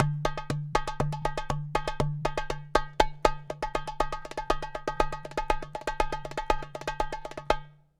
Doumbek 09.wav